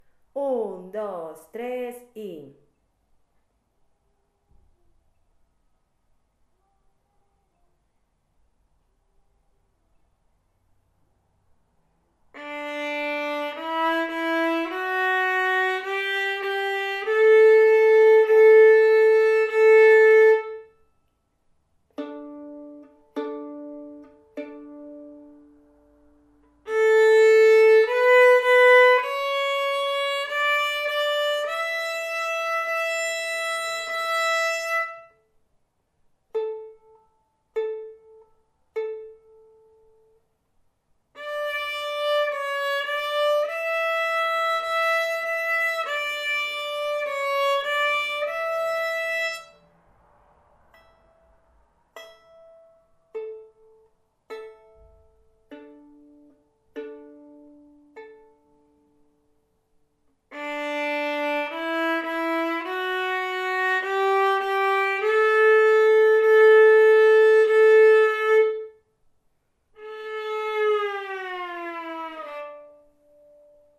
De cada una de ellas hay un audio con la música del piano que va a acompañaros y otro audio con la canción tocada por el violín.